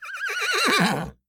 sounds_horse_neigh_01.ogg